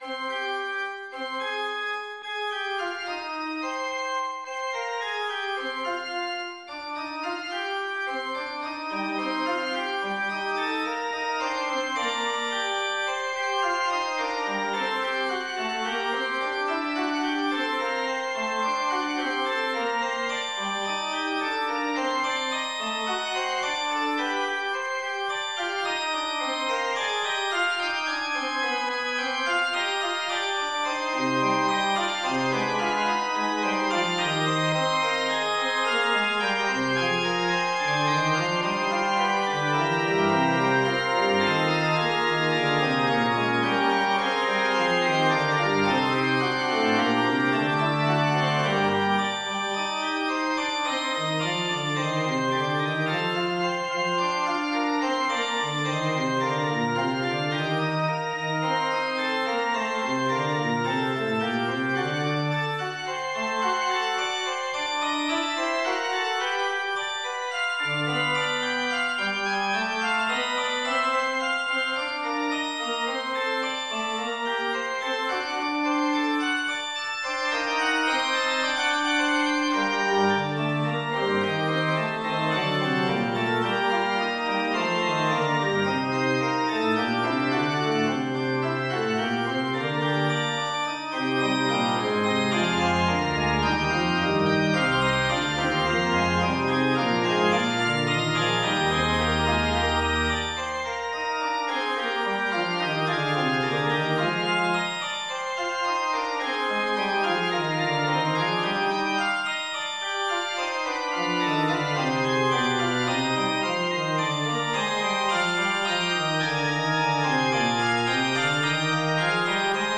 Gerre Hancock was kind enough to perform the piece at this service.
fugue03-mp3.mp3